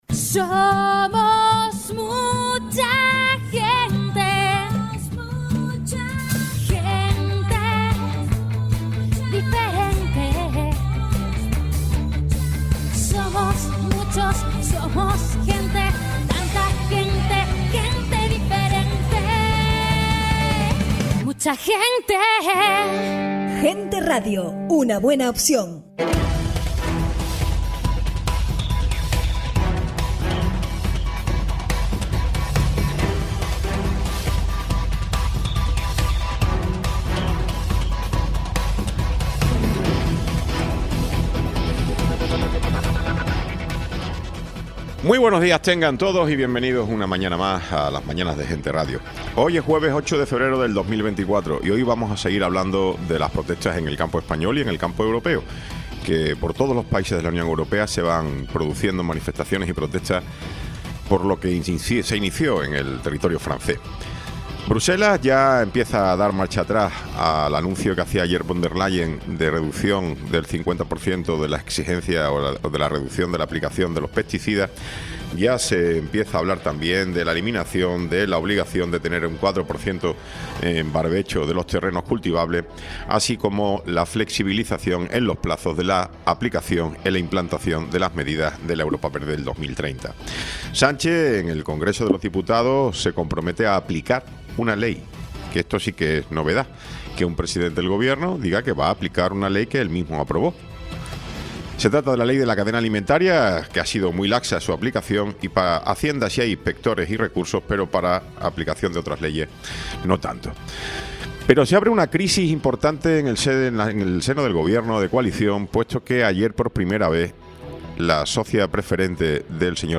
Programa sin cortes